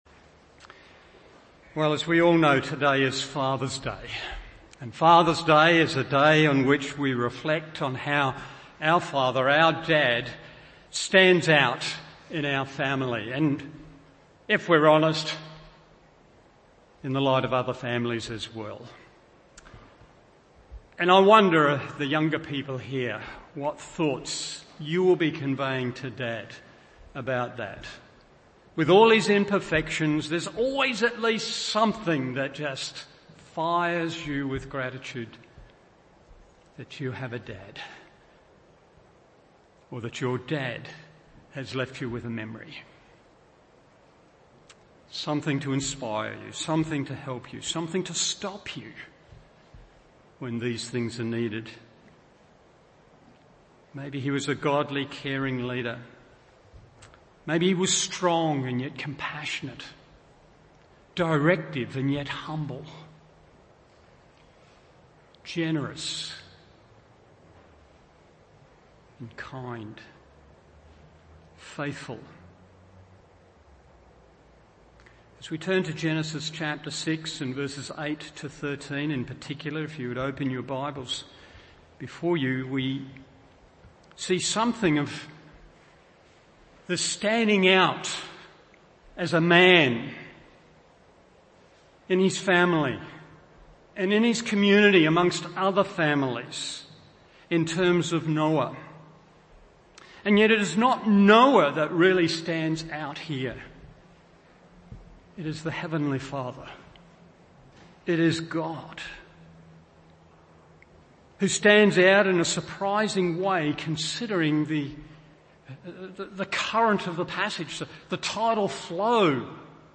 Morning Service Genesis 6:8-13 1. The Continuance of God’s Grace 2. The Operation of God’s Grace 3. The Diversity of God’s Grace…